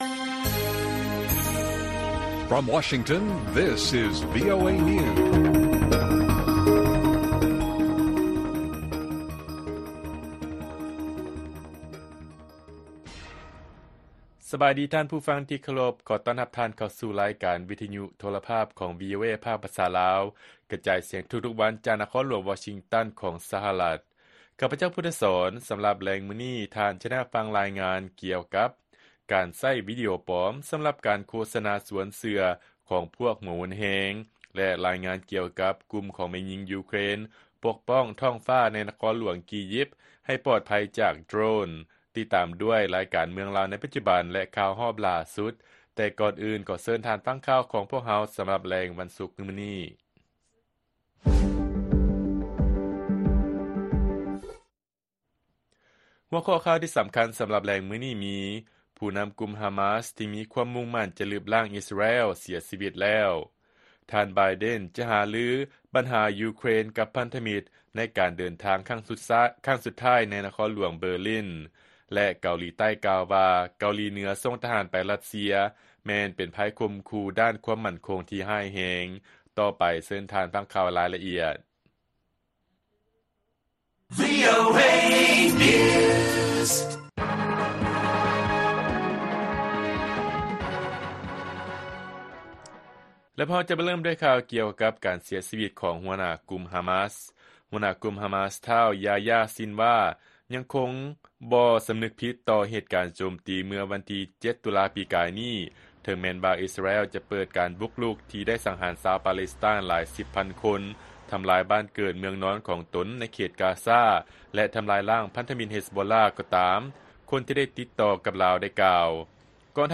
ລາຍການກະຈາຍສຽງຂອງວີໂອເອລາວ: ຜູ້ນຳກຸ່ມ ຮາມາສ ທີ່ມີຄວາມມຸ່ງໝັ້ນຈະລຶບລ້າງ ອິສຣາແອລ ເສຍຊີວິດແລ້ວ